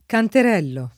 canterellare v.; canterello [